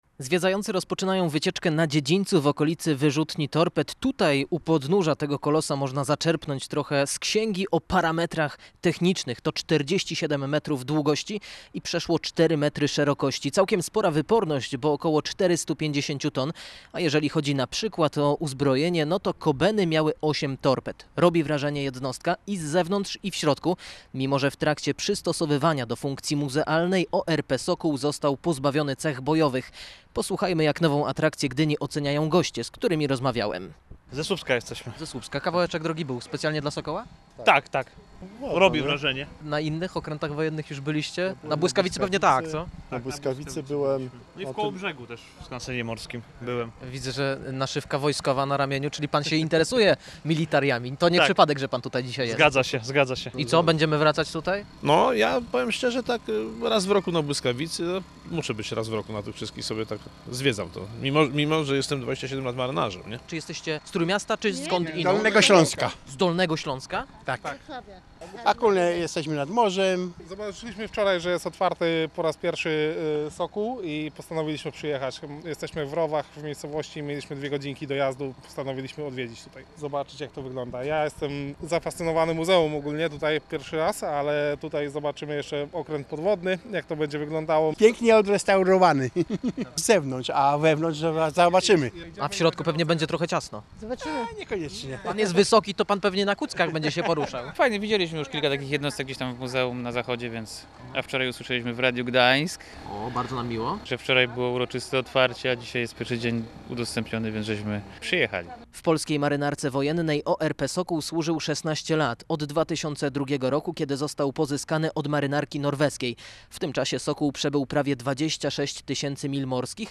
Obok wyjątkowego eksponatu na dziedzińcu Muzeum Marynarki Wojennej w Gdyni